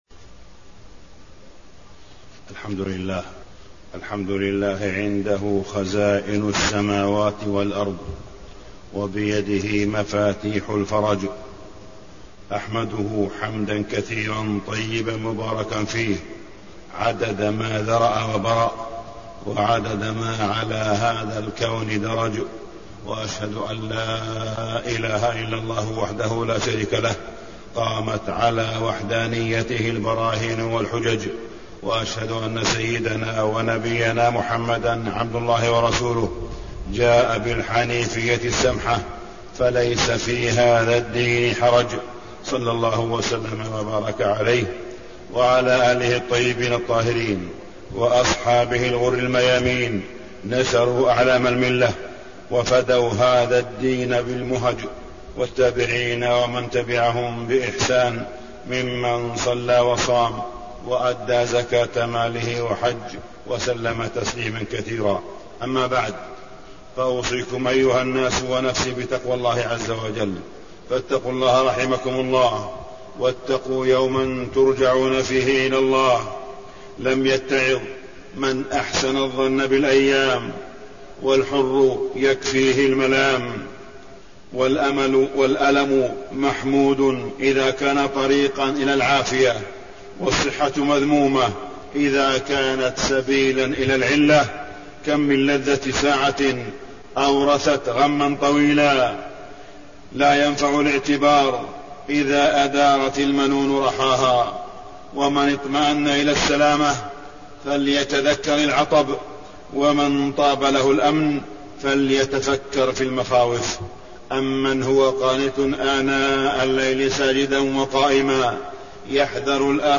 تاريخ النشر ٢٠ رجب ١٤٣١ هـ المكان: المسجد الحرام الشيخ: معالي الشيخ أ.د. صالح بن عبدالله بن حميد معالي الشيخ أ.د. صالح بن عبدالله بن حميد تبادل المنافع والنهي عن الغش